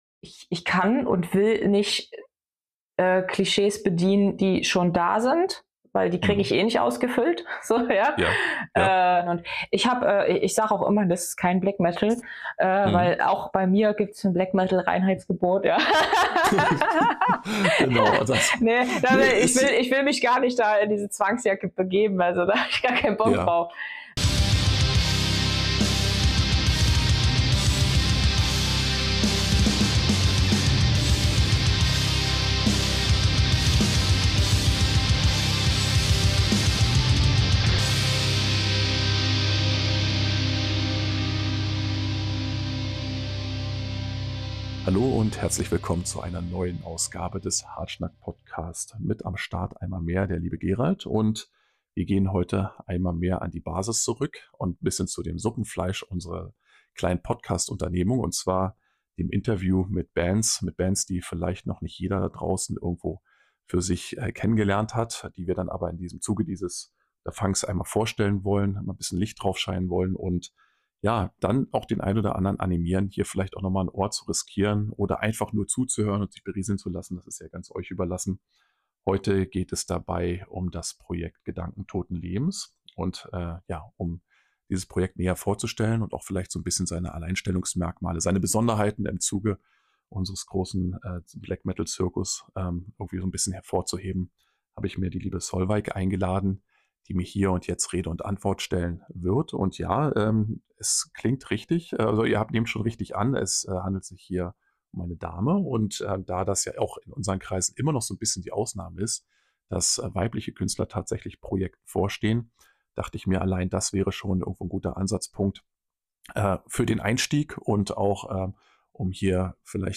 Neben der Musik geht es u.a. um den Mehrwert des kreativen Schaffens, die Rolle der Frau – damals in der DDR und heute, den Blick auf die Elterngeneration und sogar um ein paar leicht philosophische Ausflüge. Ein Gespräch zwischen Schwarzmetall, Zeitgeist und Lebensrealität – roh, ehrlich und weit über die Szene hinaus spannend.